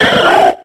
adds gen 7 icons & cries